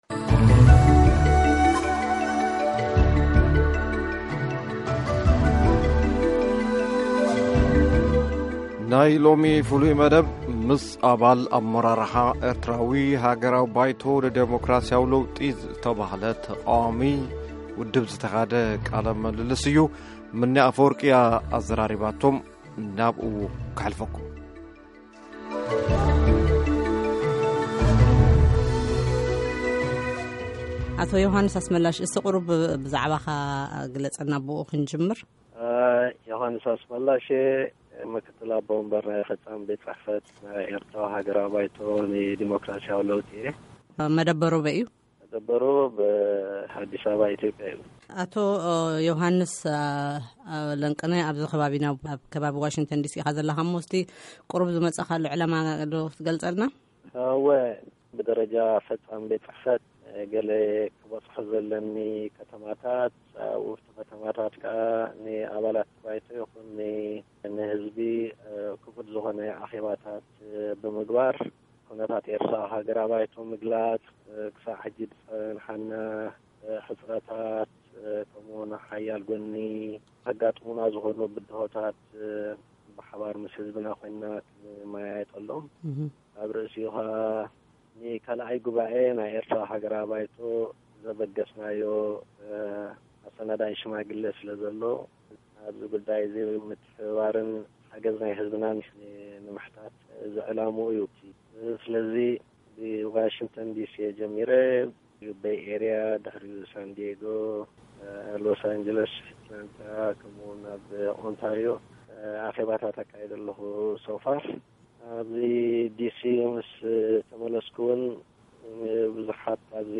ቀዳማይ ክፋል ቃለ-ምልልስ